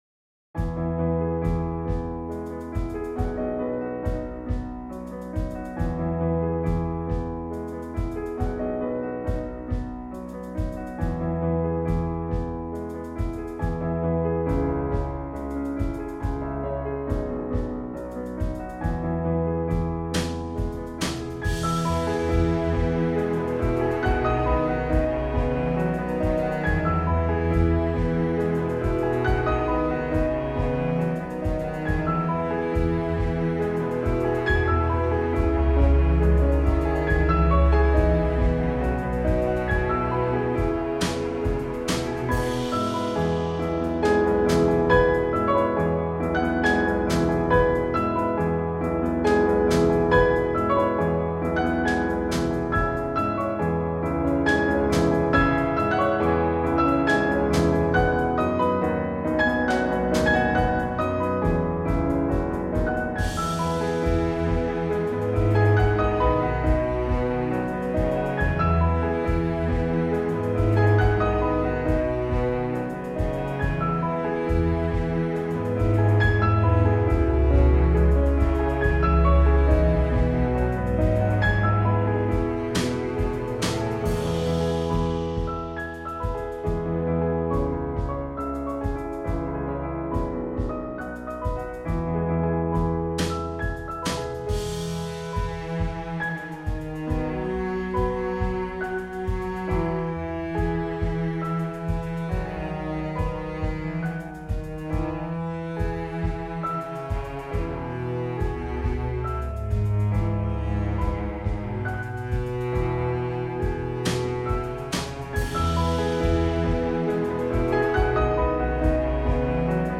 un album musical composé par l’intelligence artificielle
la bande-son de ce chapitre